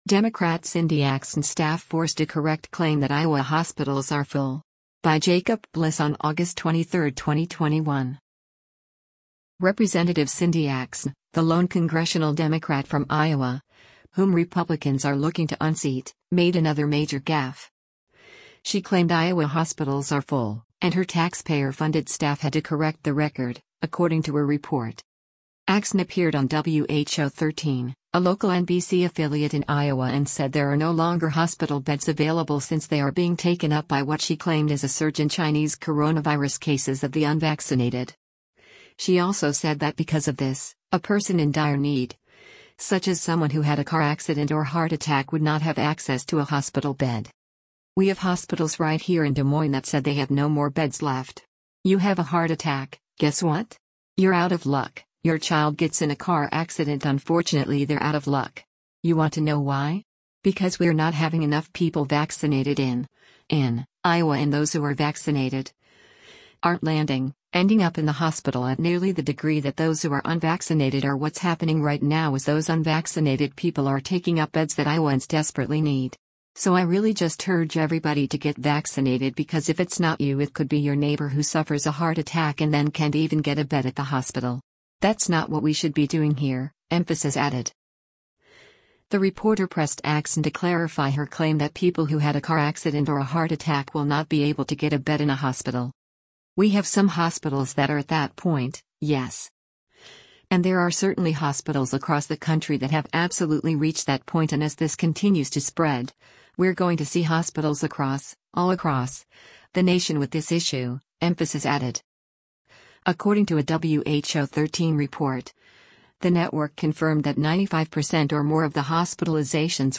Axne appeared on WHO13, a local NBC affiliate in Iowa and said there are no longer hospital beds available since they are being taken up by what she claimed is a surge in Chinese coronavirus cases of the unvaccinated.
The reporter pressed Axne to clarify her claim that people who had a car accident or a heart attack will not be able to get a bed in a hospital: